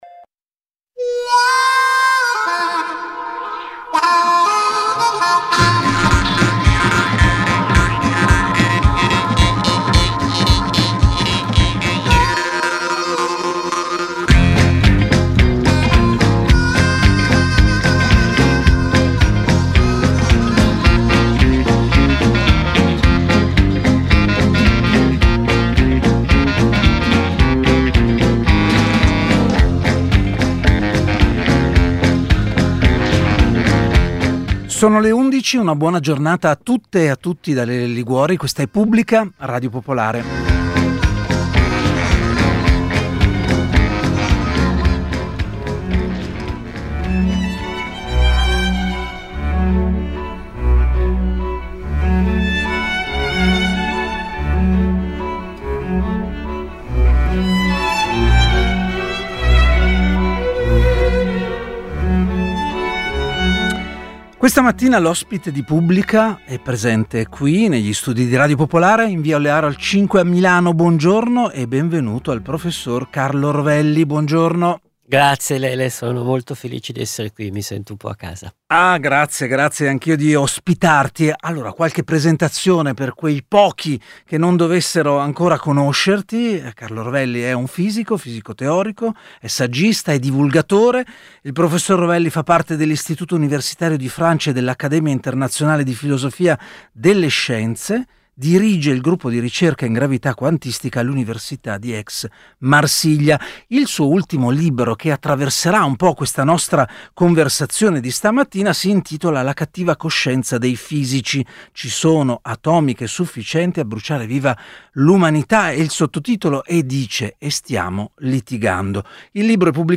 Oggi Carlo Rovelli è stato ospite della trasmissione Pubblica, a Radio Popolare.